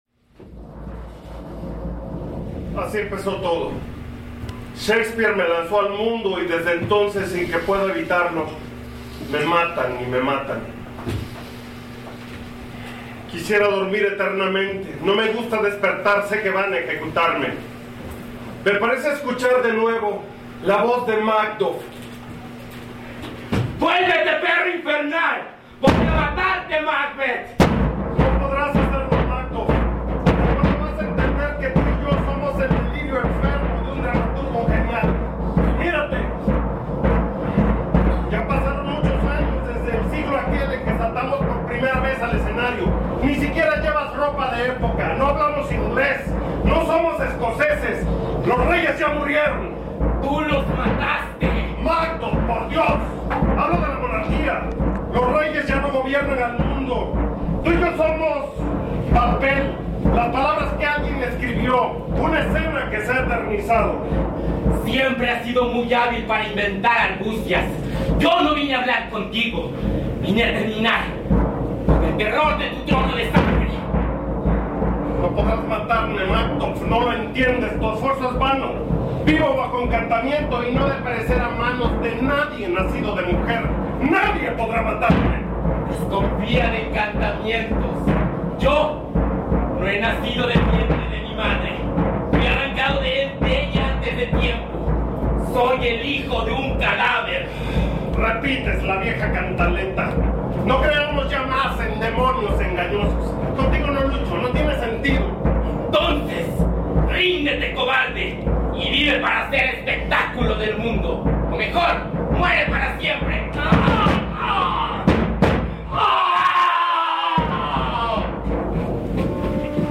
Es poco común hacer grabaciones de una obra de teatro, pero lo juzgue conveniente porque me pareció interesante la adaptación de la obra y los efectos sonoros que utilizan.
Equipo: Grabadora Sony ICD-UX80 Stereo